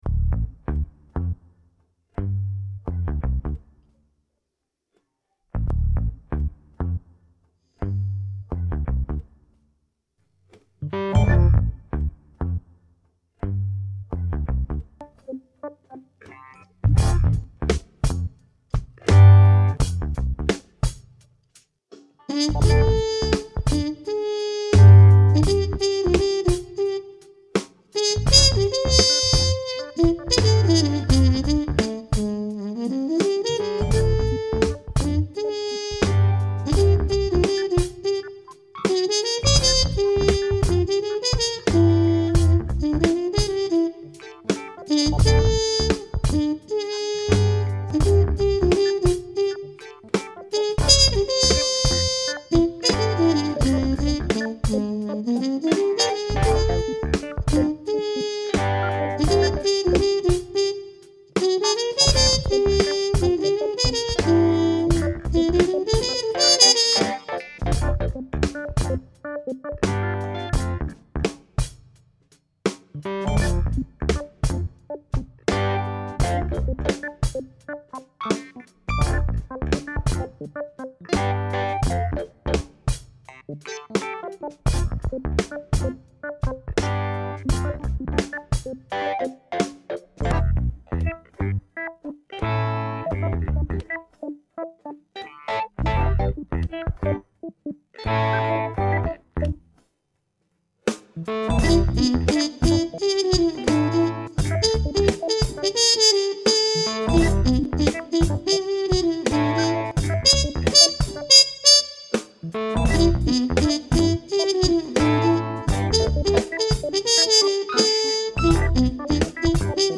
Play-Along
Trompeter:innen